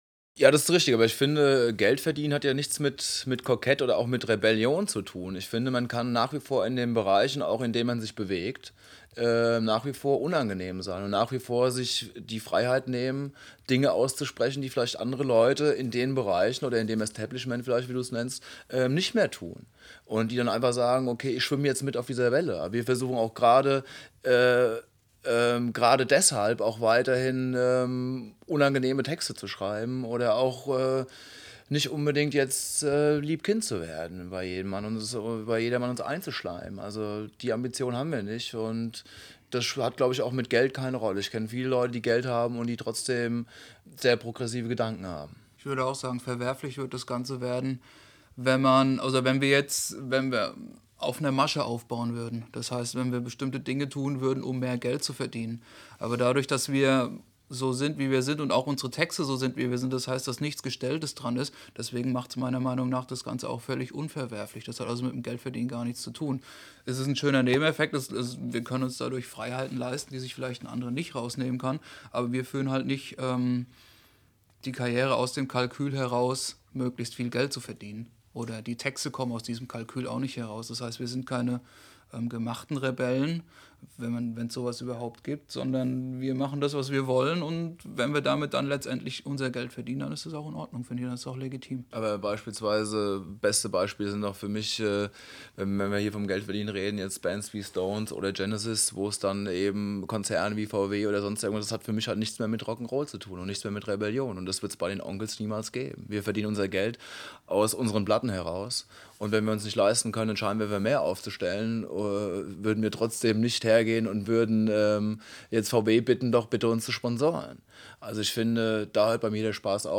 Anbei hört hier ein paar Worte der Band zum Release des Albums, direkt aus dem Presse-Kit zur Veröffentlichung.